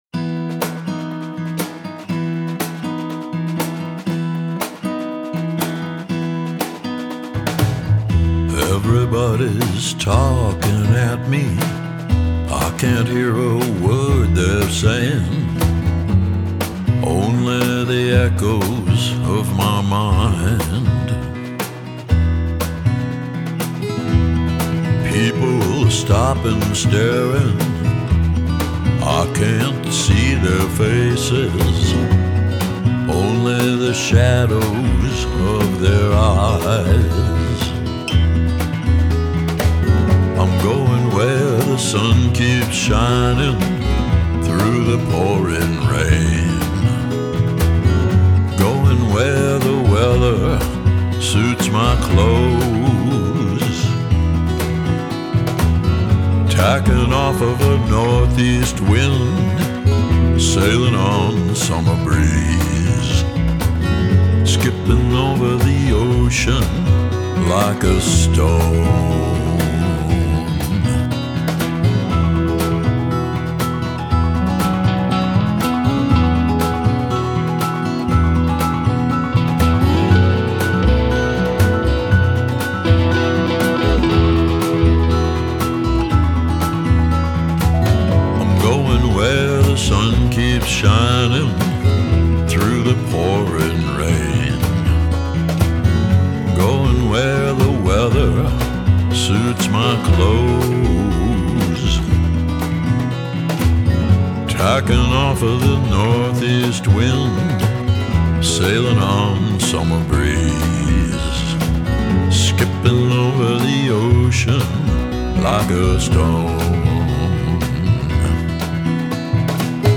Genre: Vintage Lounge, Chanson, Vocal Jazz